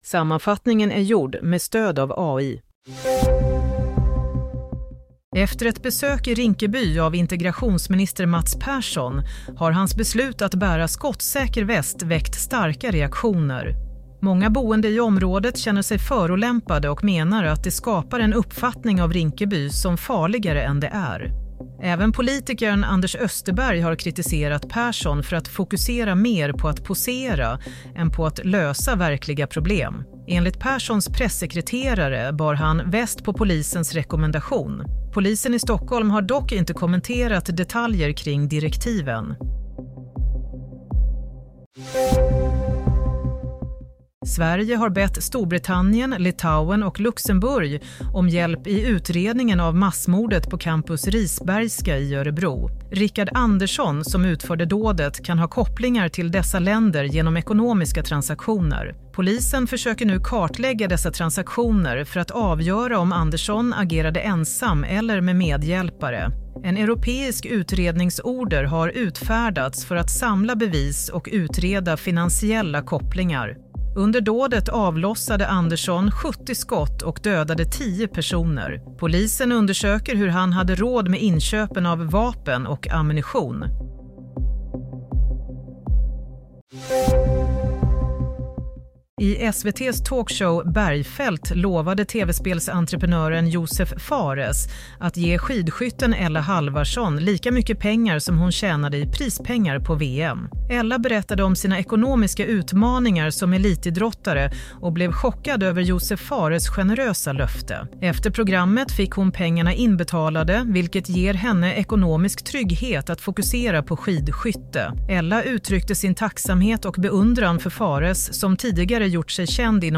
Nyhetssammanfattning - 28 februari 16:30
Sammanfattningen av följande nyheter är gjord med stöd av AI.